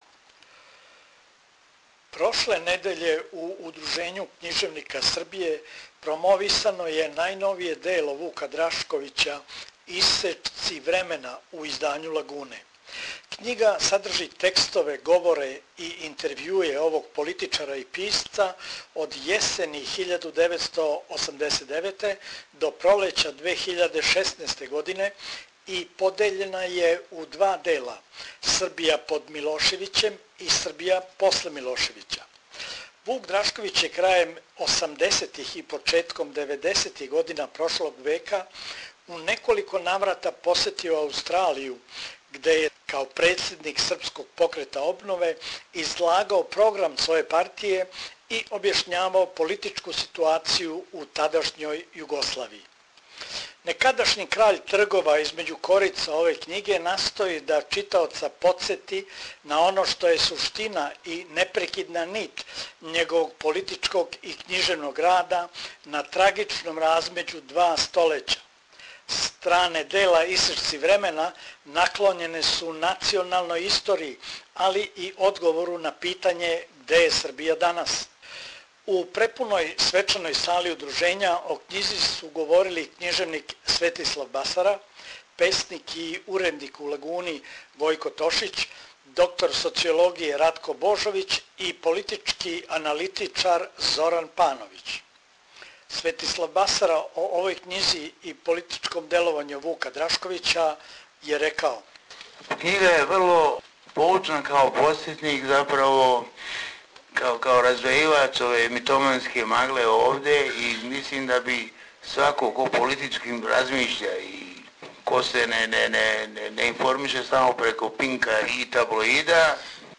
У тих двадесет и седам година стало је много тога што је утицало и на Драшковићев, али и на животе његових читалаца, речено је на промоцији књиге у Удружењу књижевника Србије